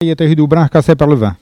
Langue Maraîchin
Patois - archives
Catégorie Locution